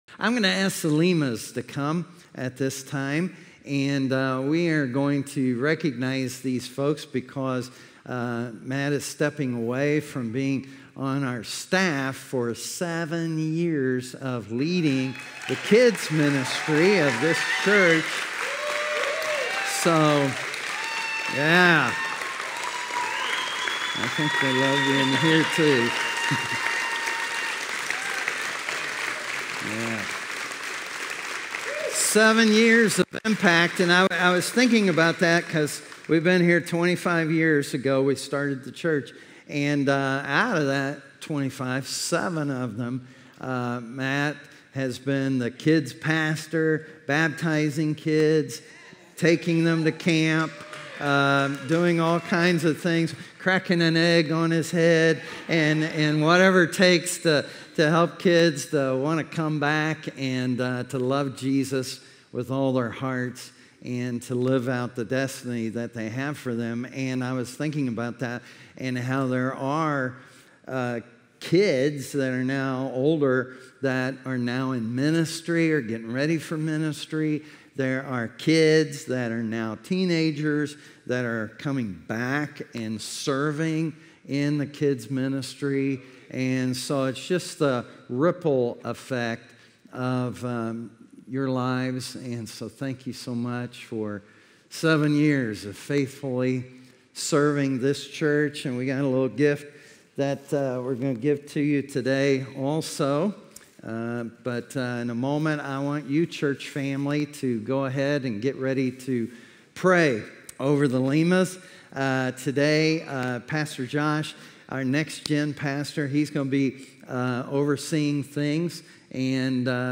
A message from the series "Reach One More."